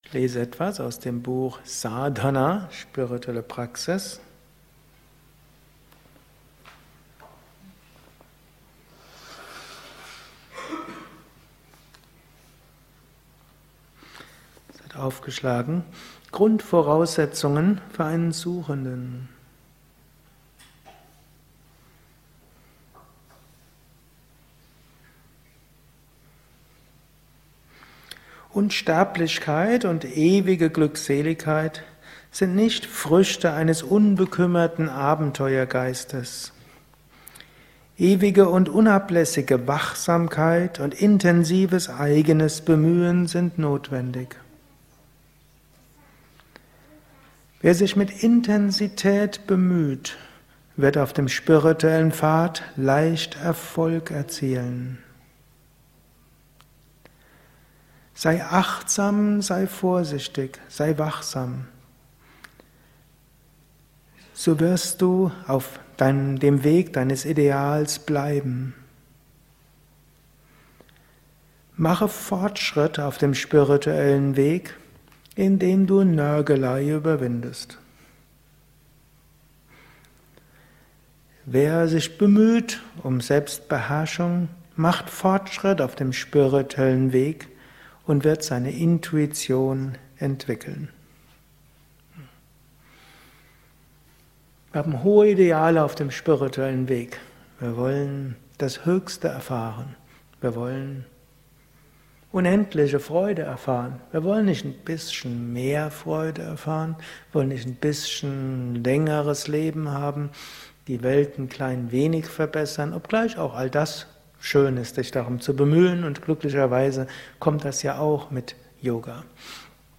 kurzer Vortrag als Inspiration für den heutigen Tag von und mit
eines Satsangs gehalten nach einer Meditation im Yoga Vidya